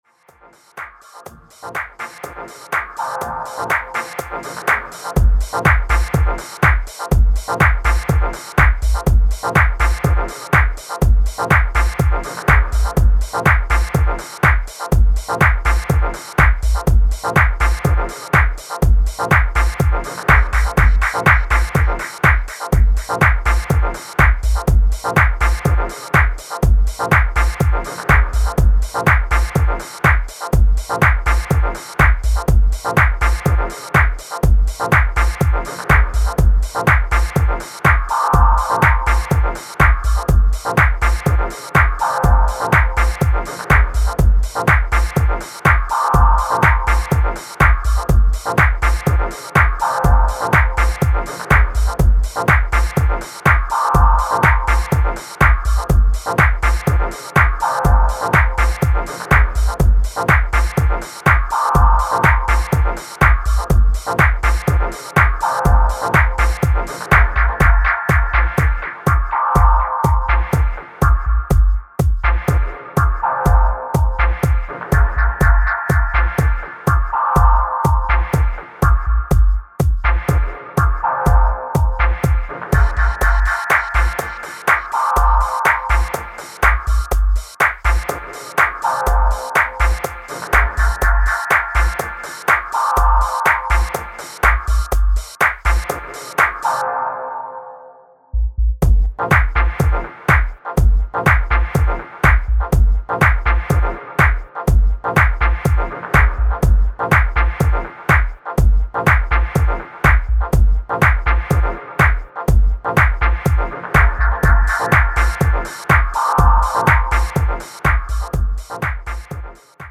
supplier of essential dance music
House Techno Dub